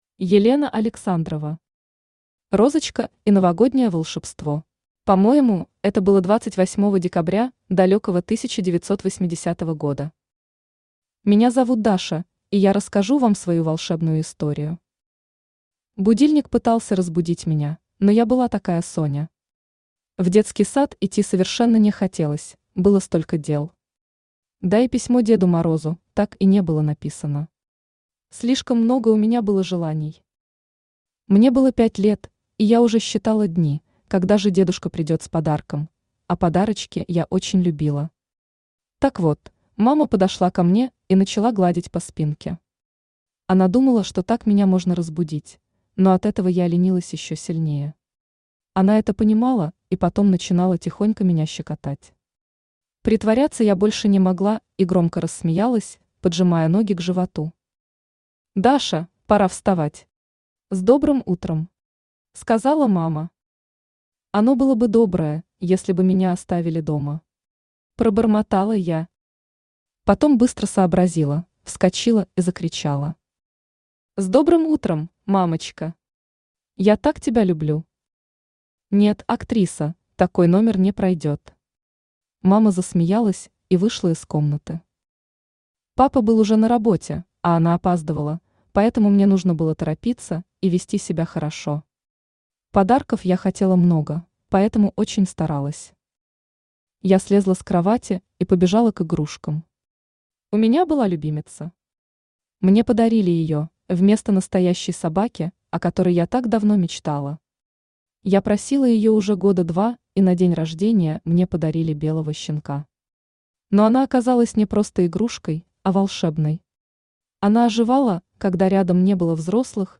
Аудиокнига Розочка и Новогоднее волшебство | Библиотека аудиокниг